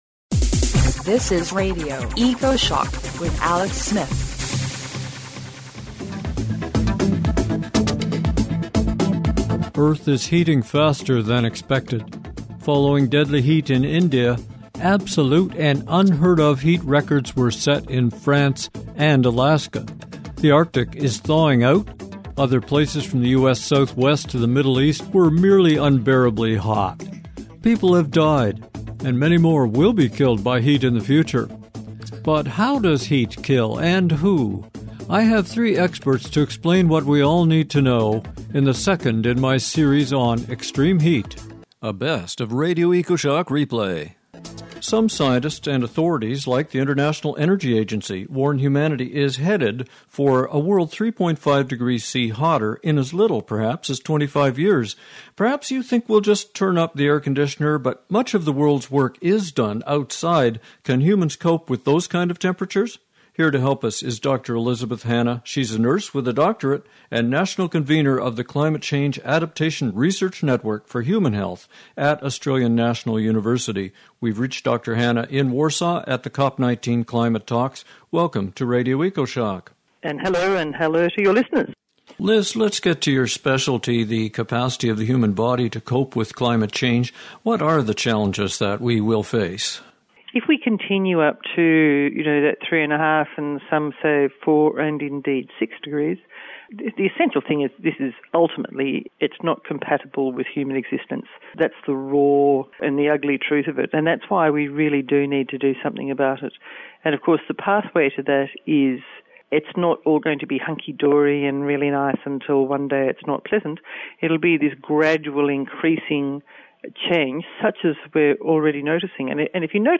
Around the world, people die and many more will be killed by heat in the future. But how does heat kill? and who? A compilation from the best of Radio Ecoshock.